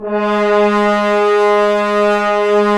Index of /90_sSampleCDs/Roland LCDP06 Brass Sections/BRS_F.Horns 1/BRS_FHns Ambient
BRS F.HRNS09.wav